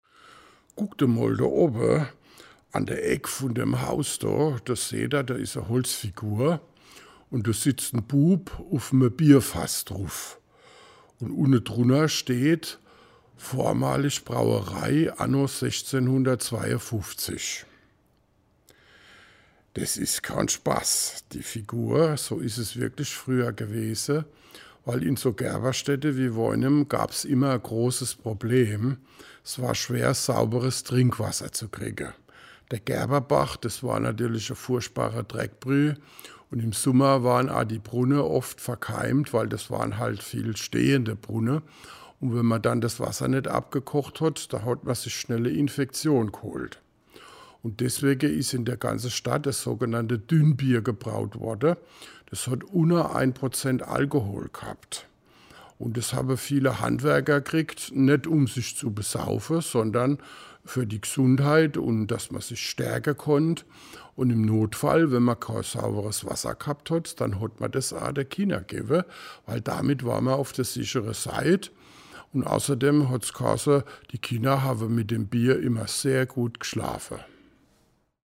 Mit Mundart unterwegs in der Altstadt
An 9 Stationen in der Innenstadt können Besucher über einen QR-Code mit dem Smartphone Anekdoten in Mundart anhören.